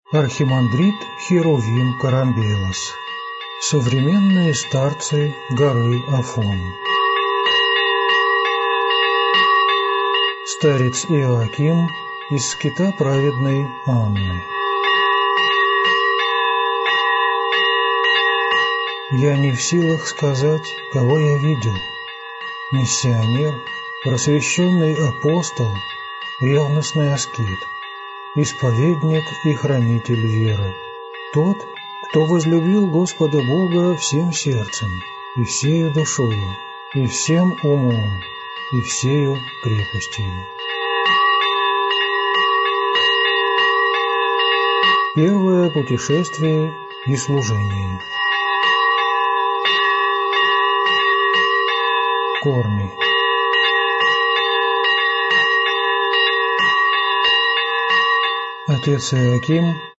Аудиокнига Старец Иоаким из скита Праведной Анны | Библиотека аудиокниг